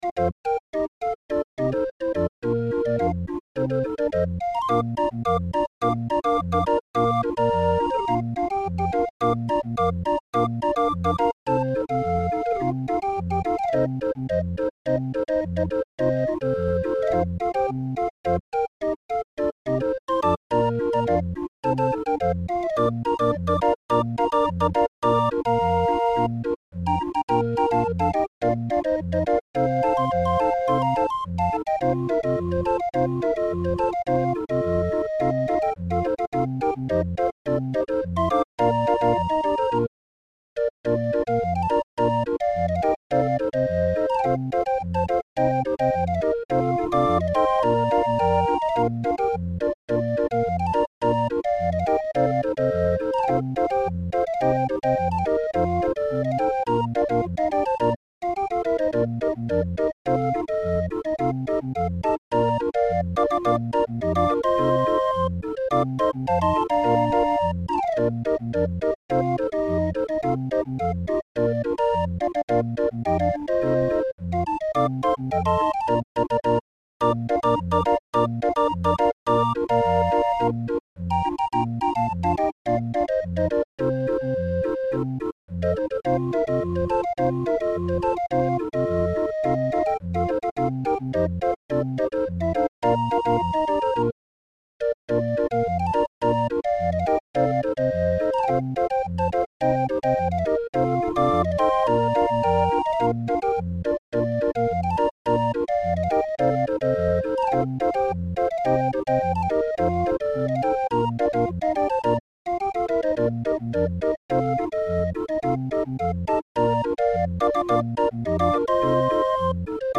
Muziekrol voor Raffin 20-er